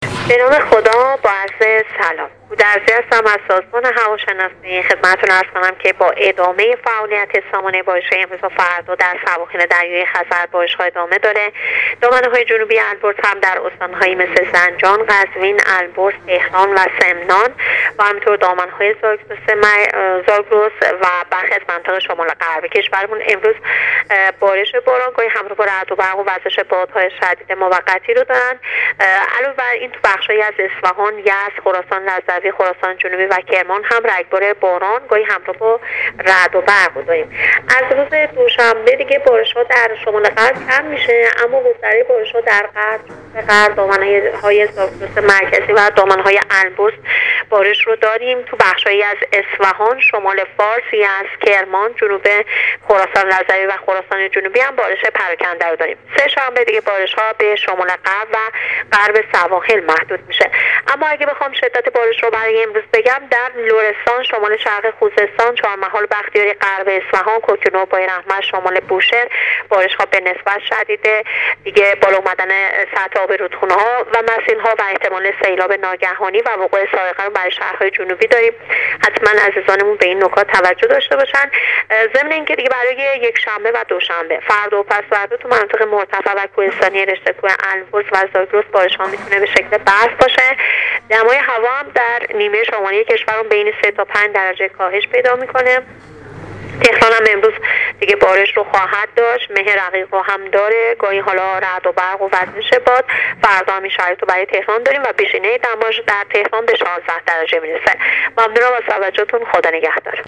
گزارش رادیو اینترنتی وزارت راه و شهرسازی از آخرین وضعیت آب و هوای چهارم آبان/ بارش باران در سواحل خزر و دامنه‌های البرز و زاگرس ادامه دارد/ بارش‌های امروز در غرب کشور شدیدتر است